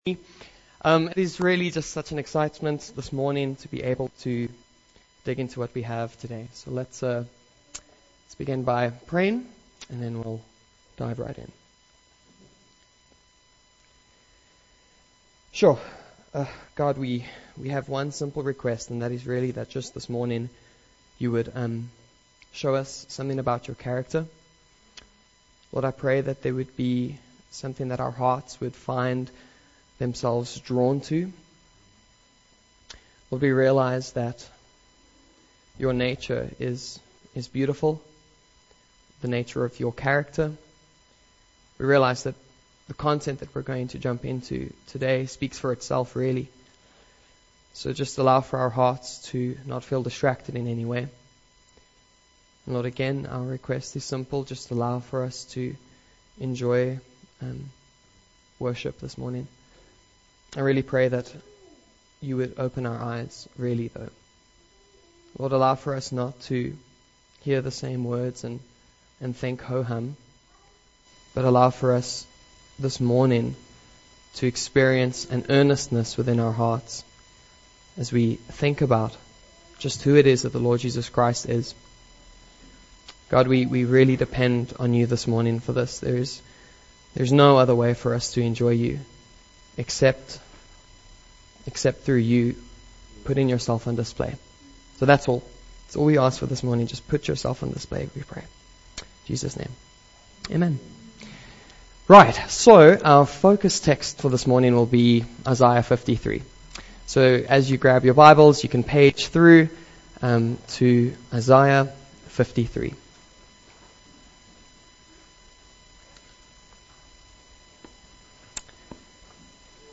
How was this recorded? Good Friday Service – Mooi River Baptist Church